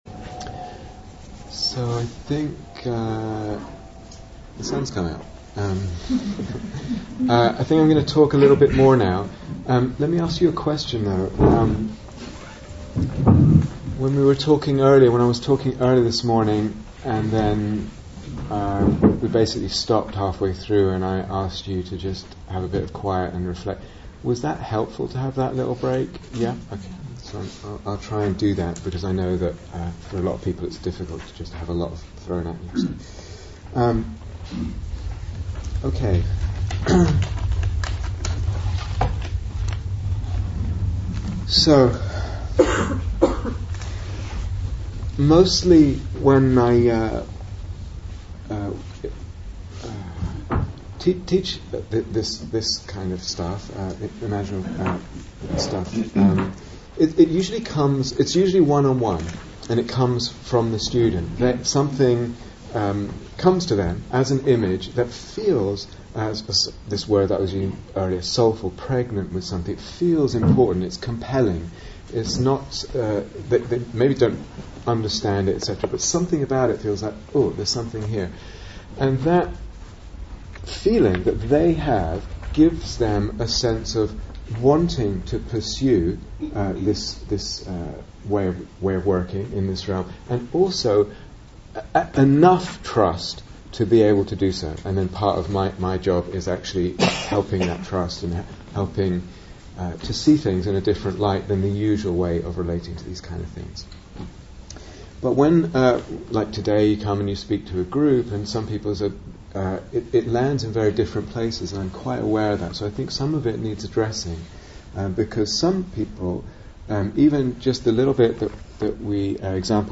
Image, Mythos, Dharma (Part Two) Download 0:00:00 35:23 Date 7th December 2014 Retreat/Series Day Retreat, London Insight 2014 Transcription I think I'm going to talk a little bit more now.